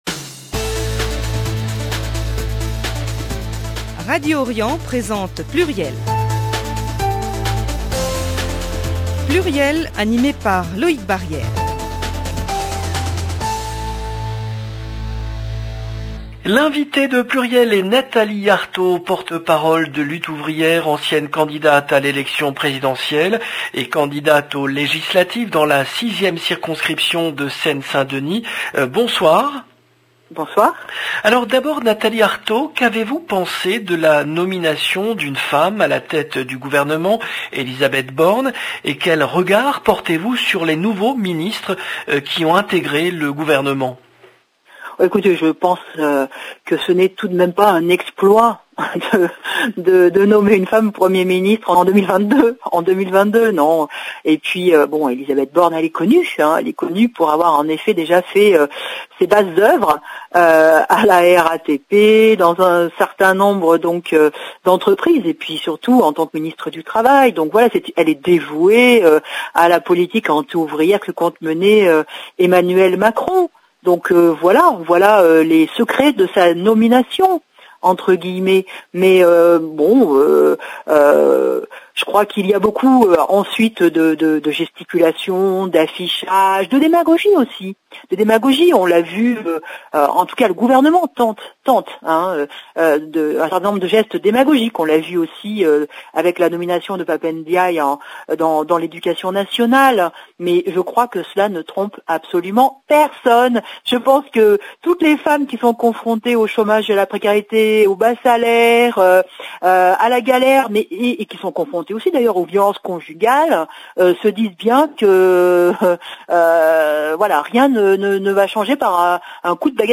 Nathalie Arthaud, porte-parole de Lutte Ouvrière